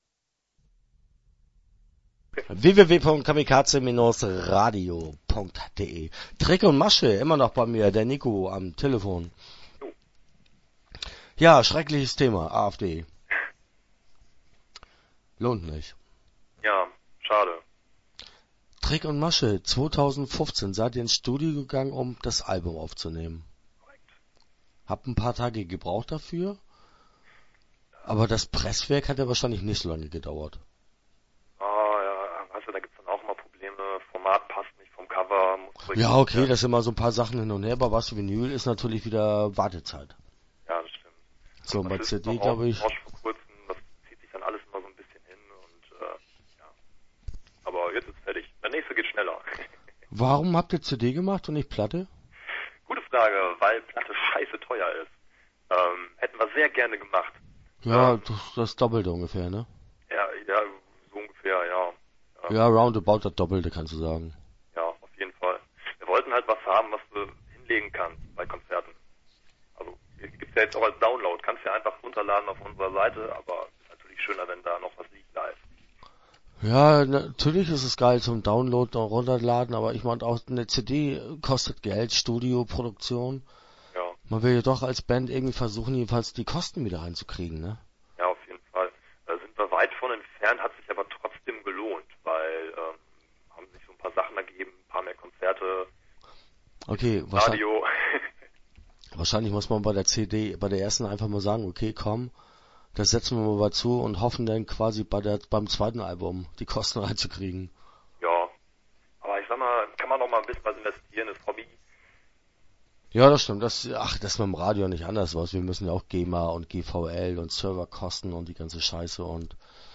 Start » Interviews » Trick und Masche